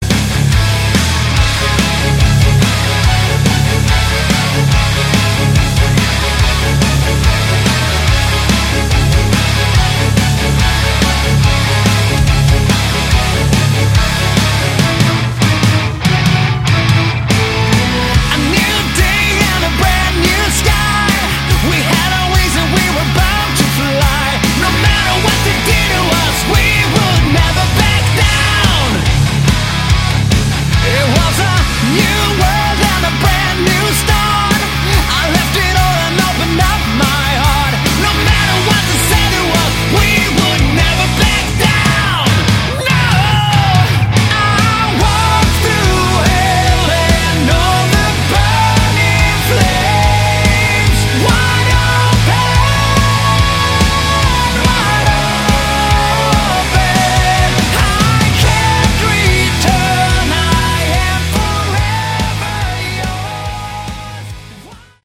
Category: Hard Rock
lead vocals, guitar
lead guitars
drums
bass